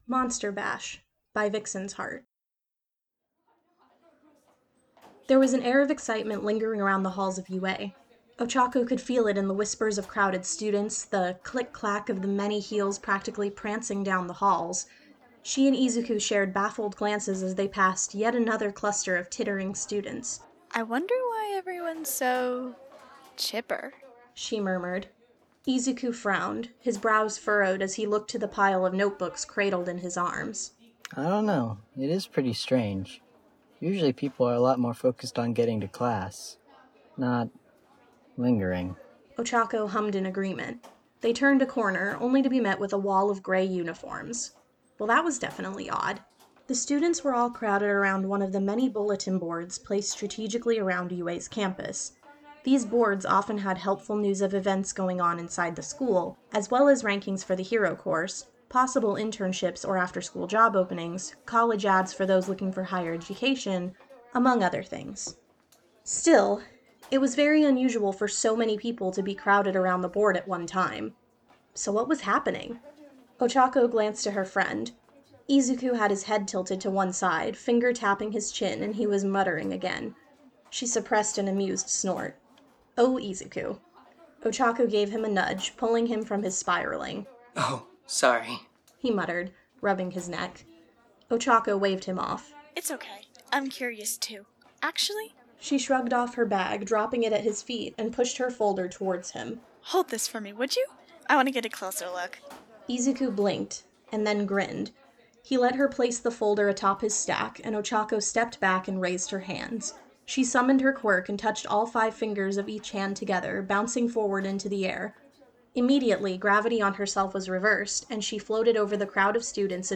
" Very noisy kids in classroom "
It has been modified to loop seamlessly.